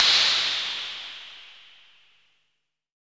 HAT FX HH OP.wav